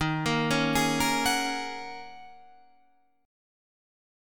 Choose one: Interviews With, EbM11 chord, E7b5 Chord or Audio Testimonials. EbM11 chord